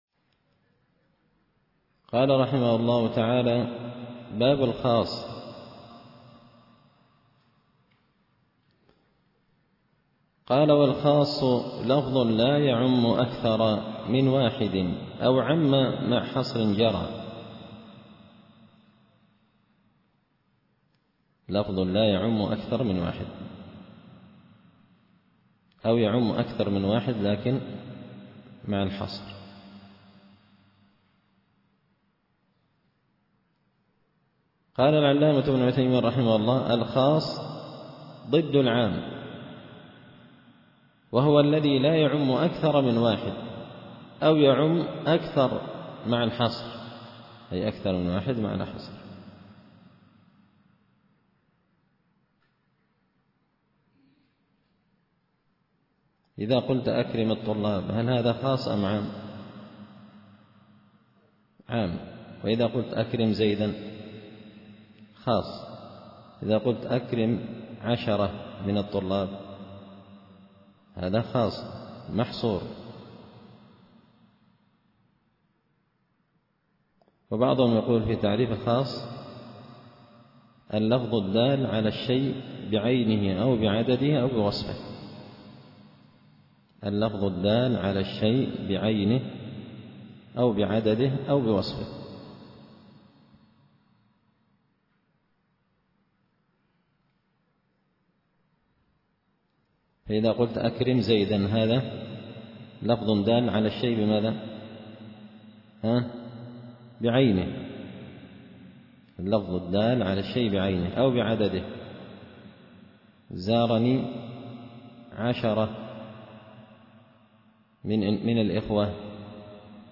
التعليقات على نظم الورقات ـ الدرس 25
دار الحديث بمسجد الفرقان ـ قشن ـ المهرة ـ اليمن